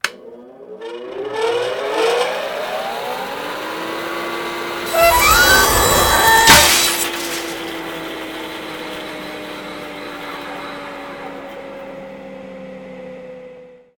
cuttingtoolfail.ogg